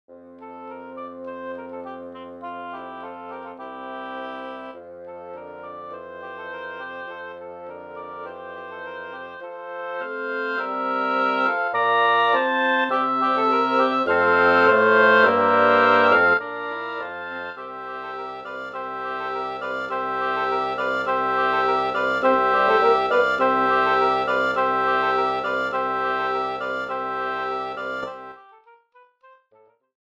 Arreglo para septeto de doble lengüeta
Formación: 3 Oboes, 1 Corno Inglés, 2 Fagotes, 1 Contrafagot